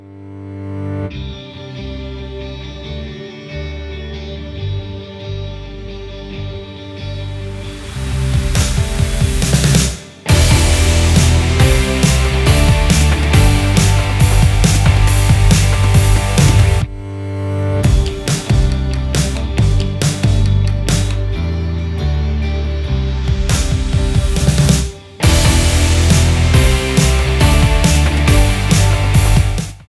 Kde seženu karaoke verzi?